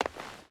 Footsteps / Stone / Stone Run 2.ogg
Stone Run 2.ogg